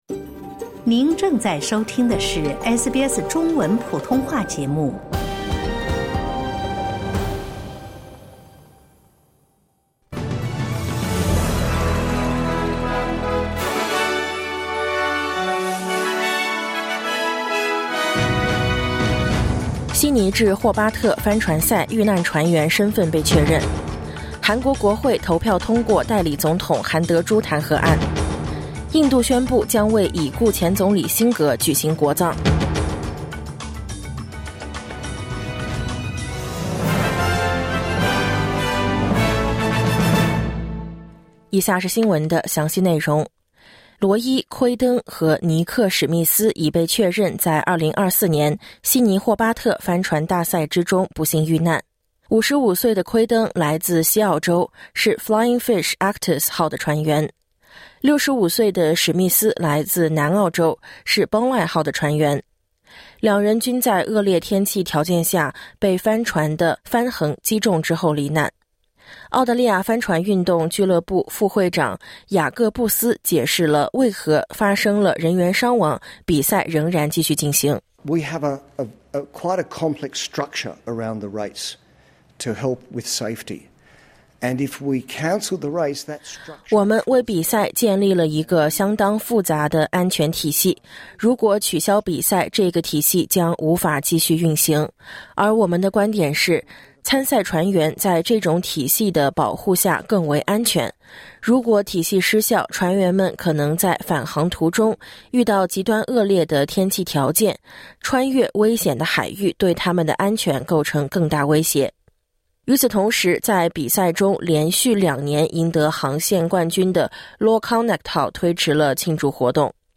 SBS早新闻（2024年12月28日）